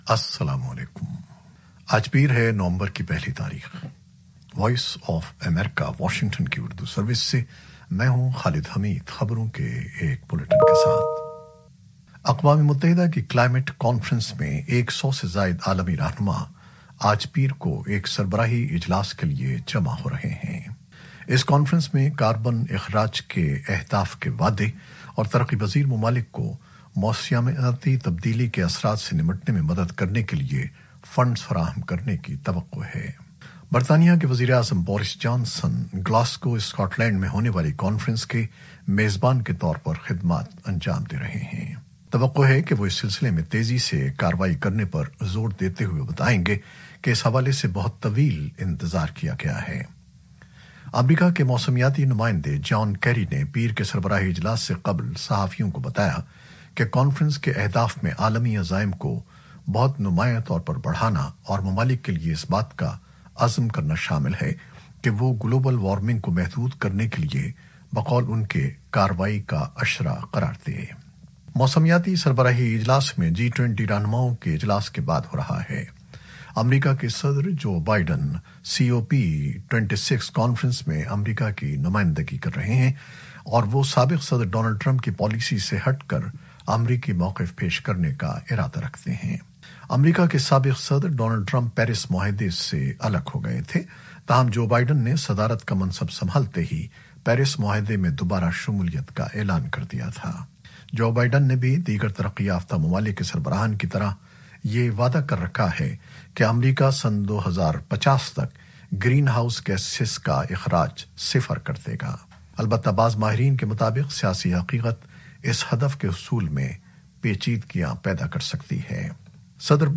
نیوز بلیٹن 2021-01-11